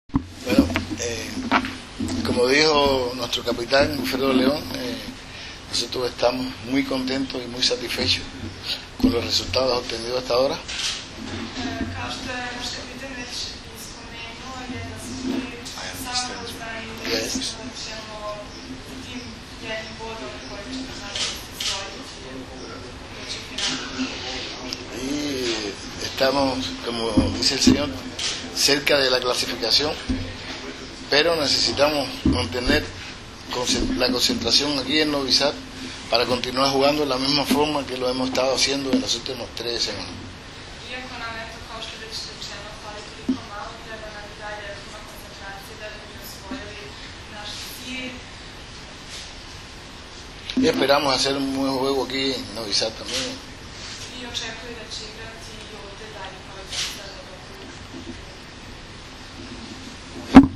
Danas je u Medija centru SPC „Vojvodina“ u Novom Sadu održana konferencija za novinare, kojoj su prisustvovali kapiteni i treneri Kube, Srbije, Rusije i Japana
IZJAVA